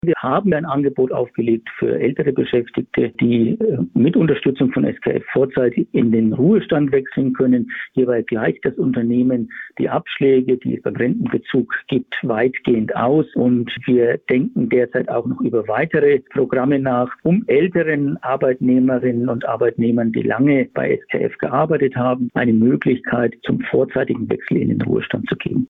Interview: Großer Stellenabbau bei SKF in Schweinfurt - PRIMATON